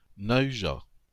Nahuja (French pronunciation: [na.yʒa]